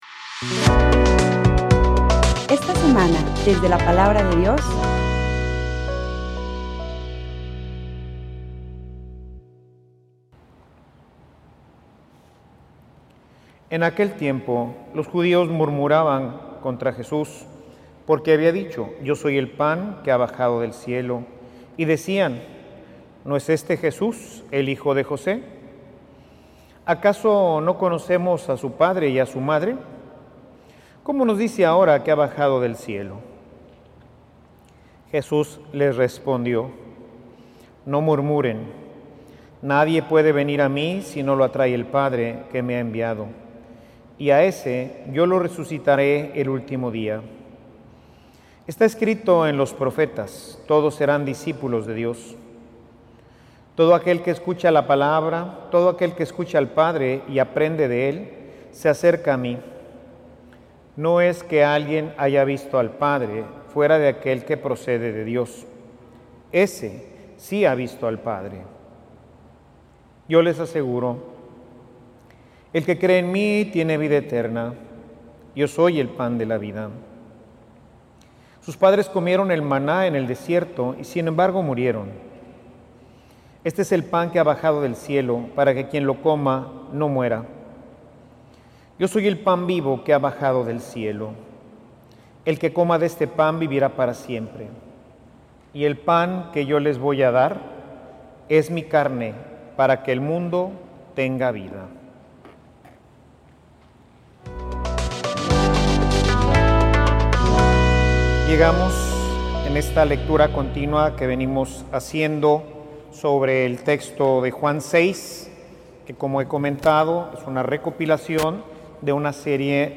Homilia_La_fe_puesta_a_prueba.mp3